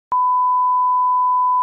BEEP (Beep Sound Effect) Sound Effects Free Download
BEEP (Beep sound effect)